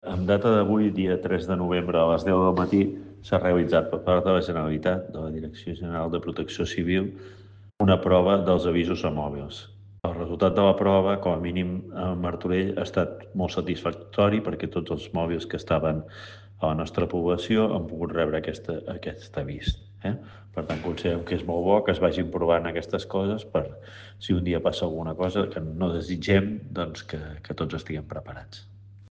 Lluís Sagarra, regidor Seguretat Ciutadana